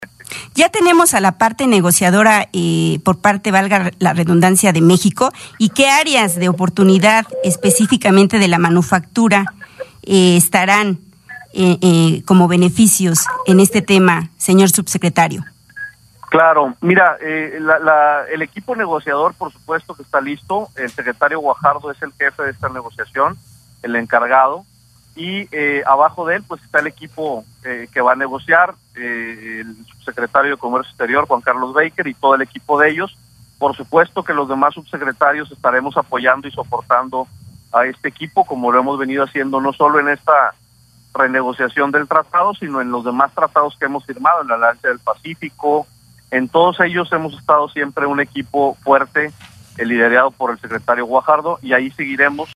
CIUDAD DE MÉXICO (01/08/2017).- Ayer por la noche en el Programa de Radio Vanguardia Industrial, que se transmite todos los lunes a las 20:00 hrs, se le preguntó al subsecretario de Industria y Comercio de la Secretaría de Economía (SE), Rogelio Garza Garza sobre si ya se tenía definido al equipo que representará a México en las negociaciones de la modernización del Tratado de Libre Comercio de América del Norte (TLCAN), mismas que inician el día 16 del presente mes.
Escuchar Audio de Rogelio Garza Garza
Audio-Entrevista-Rogelio-De-La-Garza.mp3